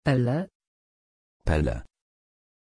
Aussprache von Pelle
pronunciation-pelle-pl.mp3